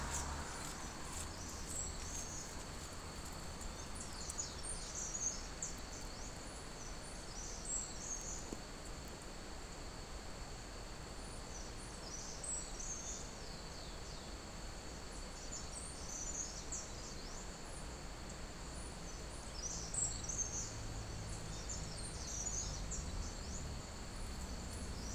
Ratona Ceja Blanca (Troglodytes solstitialis)
Nombre en inglés: Mountain Wren
Localidad o área protegida: Monteros
Condición: Silvestre
Certeza: Vocalización Grabada
Ratona-ceja-blanca.mp3